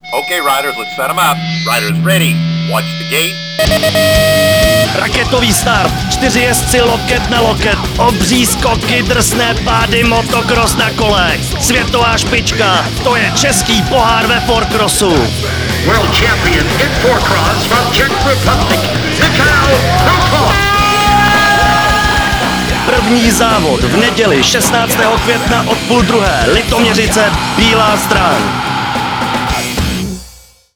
Ochutnejte" první návrh audio-spotu pro rádia.